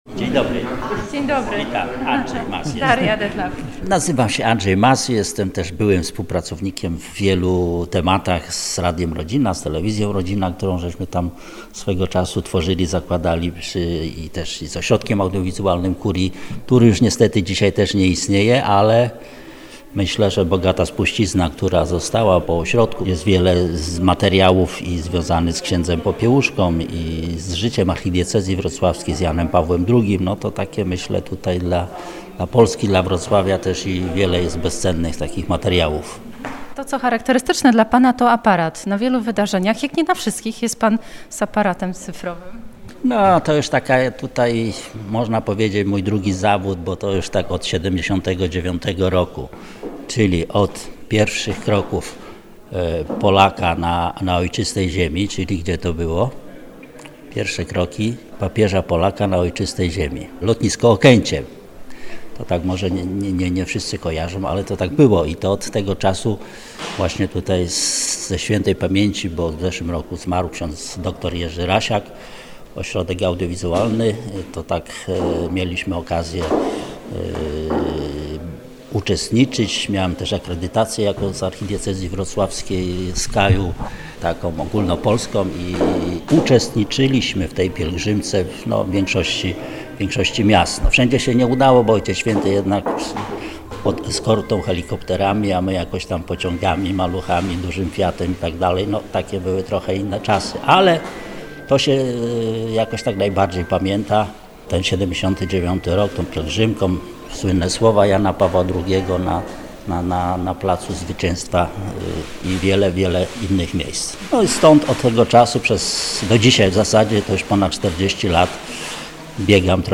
Na rozmowę zapraszamy dziś (środa 7 września) po godz. 13:10 w audycji „Dziś w Kościele”.